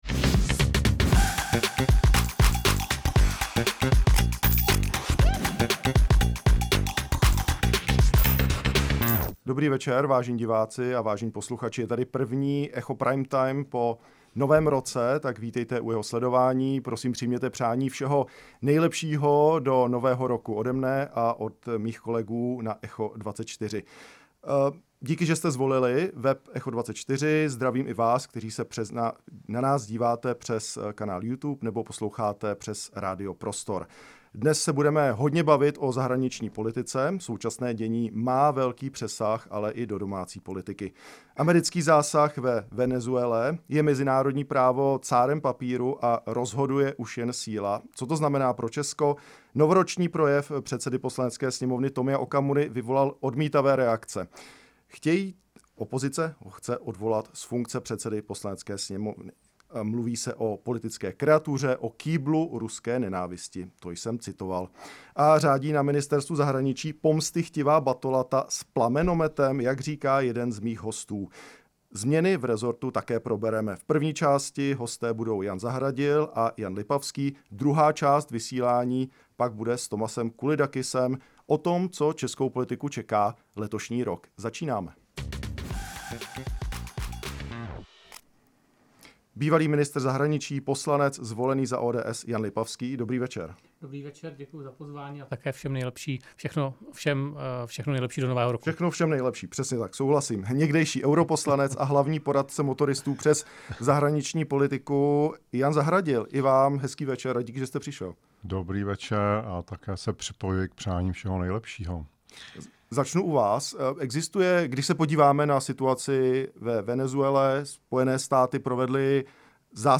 V pořadu Echo Prime Time to uvedl někdejší dlouholetý europoslanec a poradce Motoristů sobě pro zahraniční politiku Jan Zahradil. Hovořil také o tom, že o návratu před rok 1997, o kterém otevřeně mluví Rusko, nemůžeme s Moskvou smlouvat. Podle Zahradila se politická situace v Evropě může během dvou nebo tří let proměnit a s ní i postoj k Rusku, případně třeba ke členství Ukrajiny v EU.
Tématem debaty bylo mimo jiné propouštění na ministerstvu zahraničních věcí.